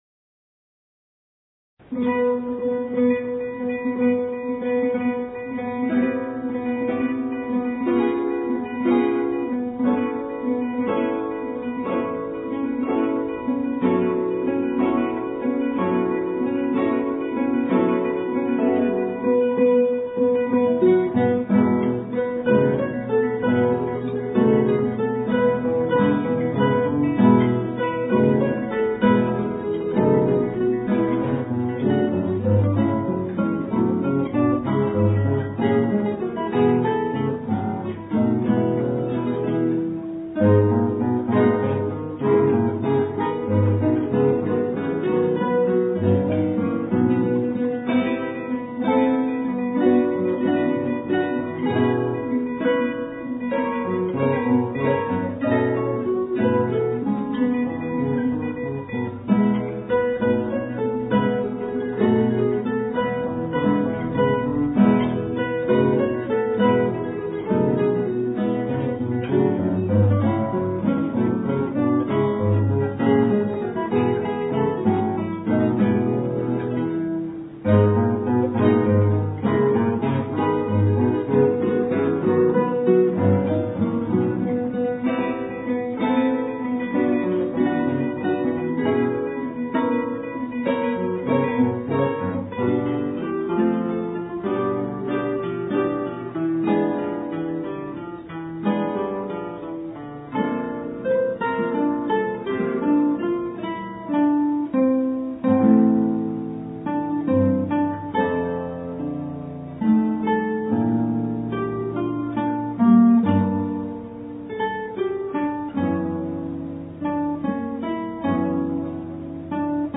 府大クラシックギタークラブＯＢ有志